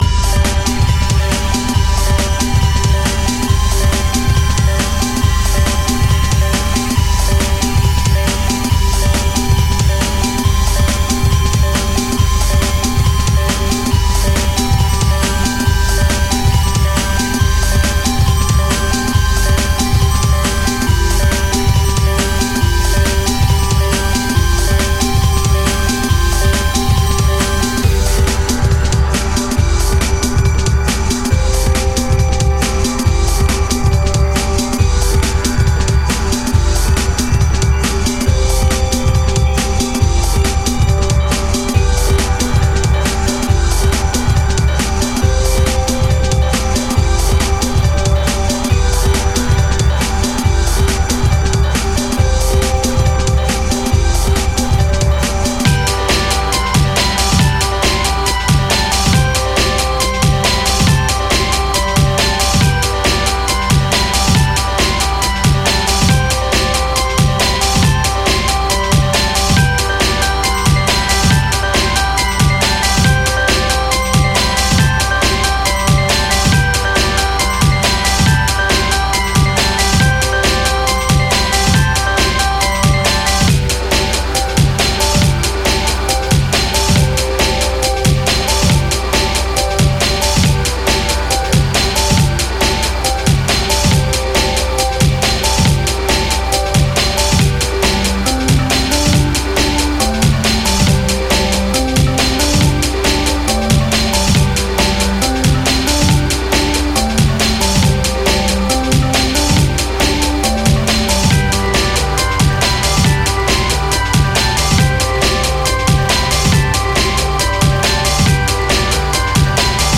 Lose yourself in the electronic.